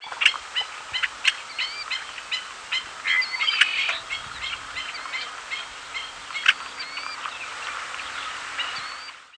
Yellow-headed Blackbird diurnal flight calls
Male in flight giving "khek" and "k-dk" calls. Black-necked Stilt, Killdeer, and Red-winged Blackbird calling in the background.